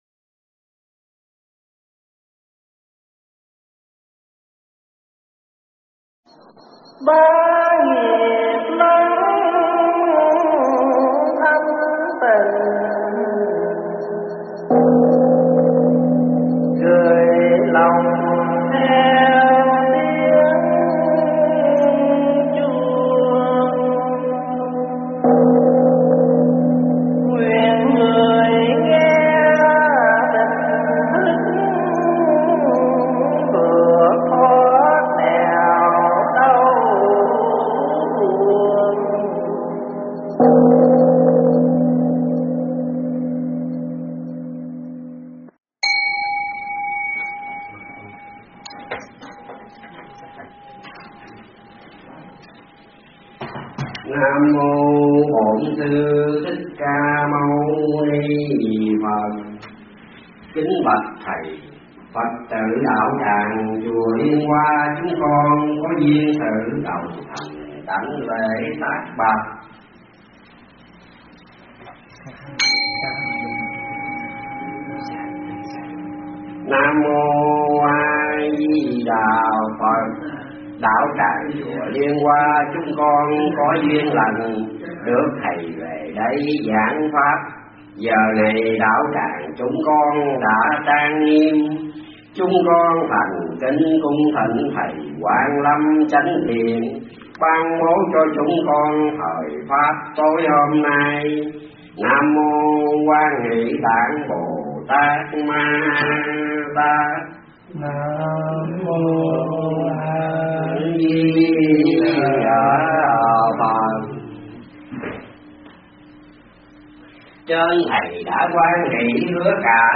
Nghe Mp3 thuyết pháp Trân Quý Thời Gian